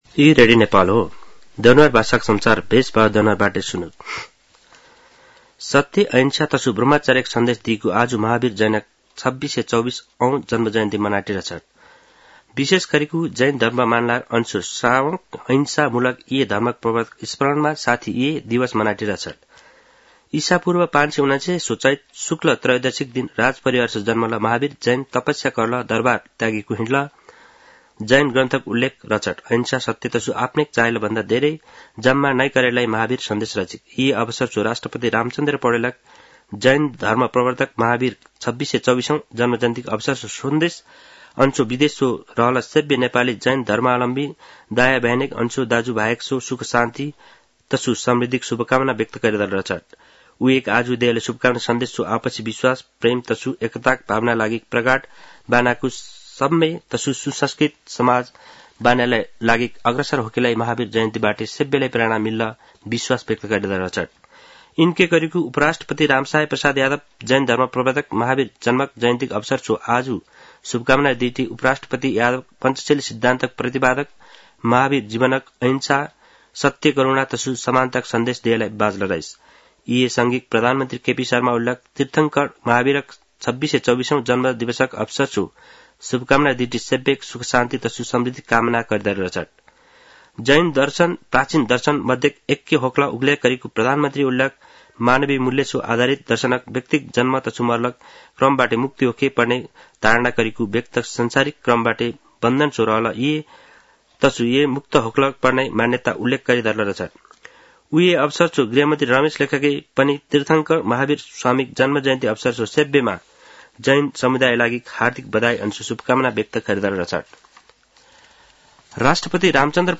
दनुवार भाषामा समाचार : २८ चैत , २०८१